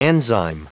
Prononciation du mot enzyme en anglais (fichier audio)
Prononciation du mot : enzyme